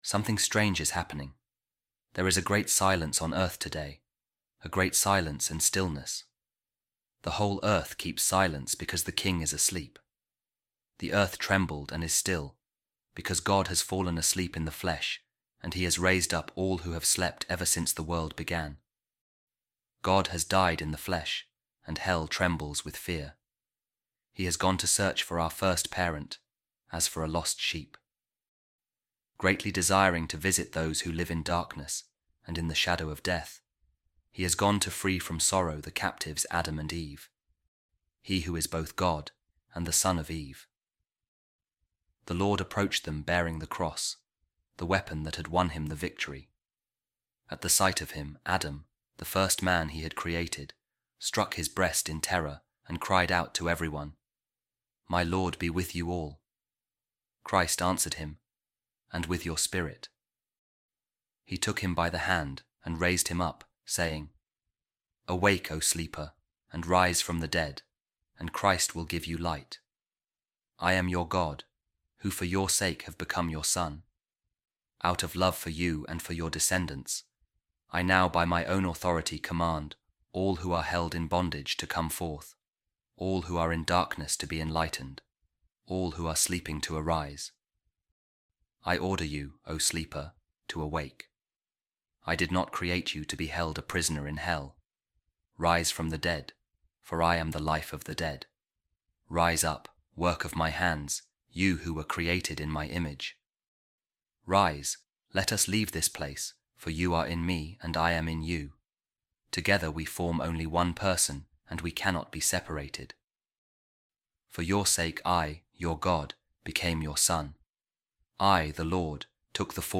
Office Of Readings | Holy Saturday | A Reading From An Ancient Homily For Holy Saturday
holy-saturday-readings-ancient-homily.mp3